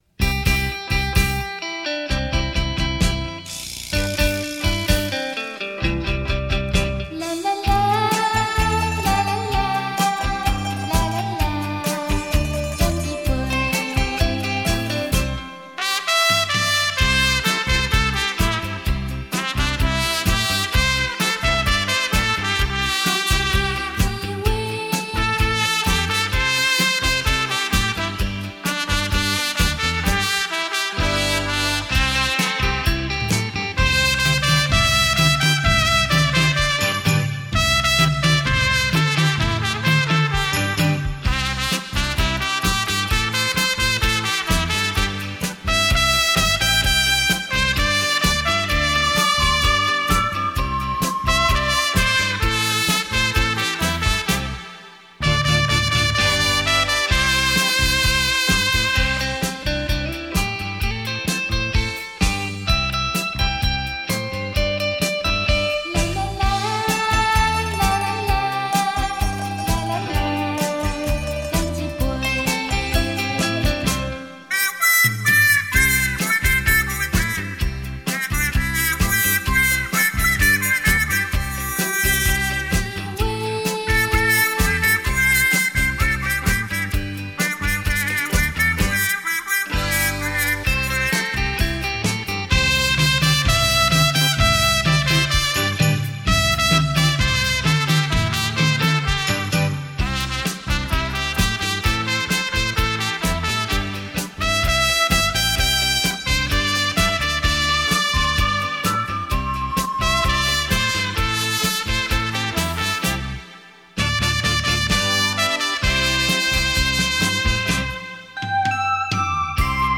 小喇叭各类乐风魔幻演奏
一听再听 百听不厌的名曲演奏